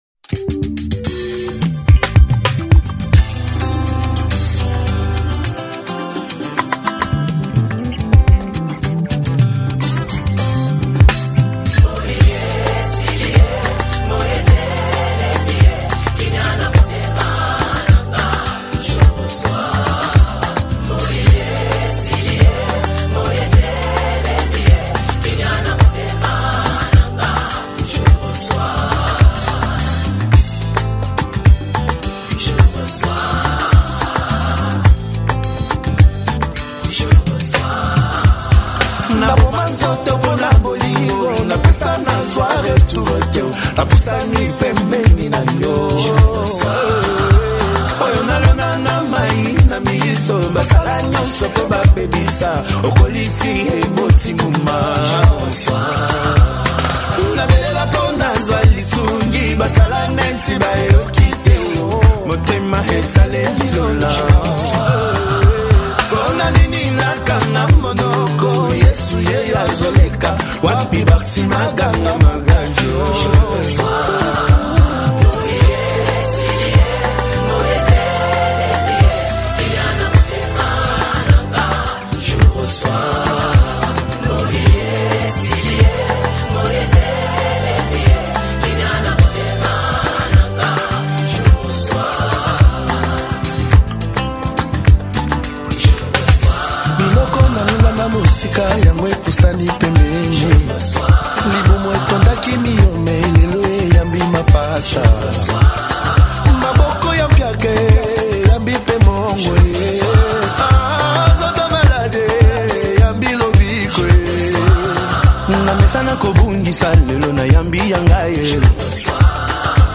Gênero: Gospel